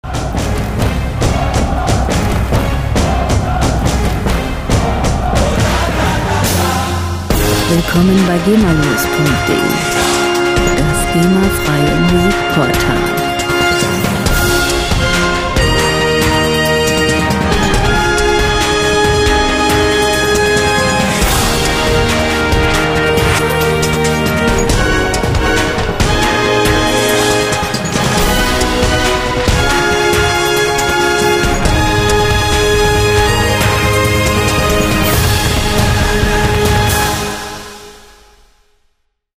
Filmmusik - Hymnen
Musikstil: Fußball-Hymne
Tempo: 138 bpm
Tonart: F-Dur
Charakter: glorreich, bombastisch
Instrumentierung: Orchester, Chor